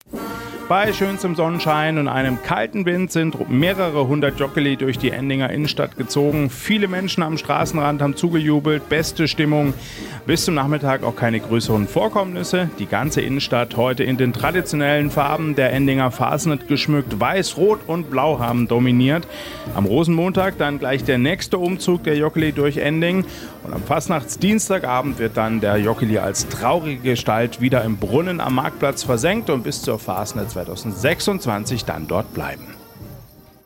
Bei strahlender Sonne und blauem Himmel schauten ihnen viele vom Straßenrand zu.
Bei schönstem Wetter ziehen in Endingen am Kaiserstuhl am Fasnachtssonntag mehrere Hundert Jokili durch die Innenstadt.